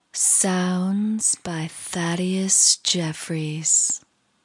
Tag: 美国 英国 女性 女孩 请求 谈话 女性 声带 说话 语音